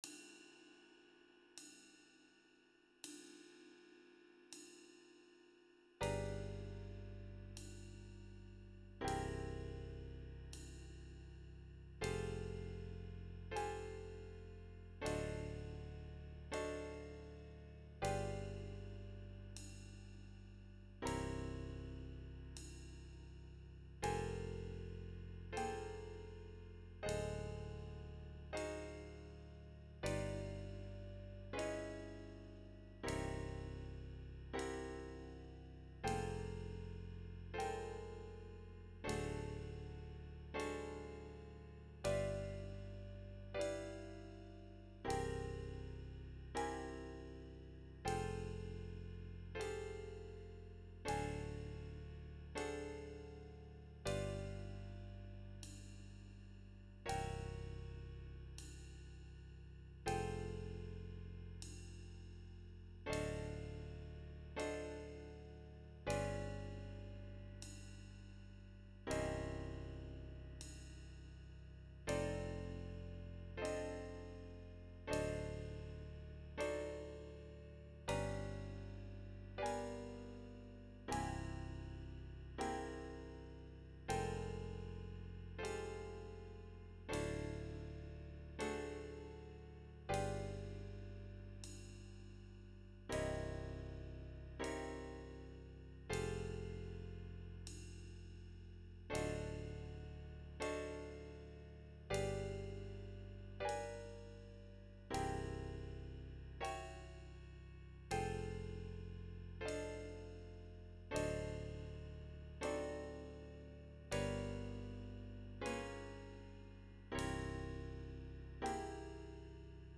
MP3 with Bass and Cymbal